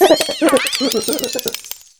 Cri de Gromago dans Pokémon HOME.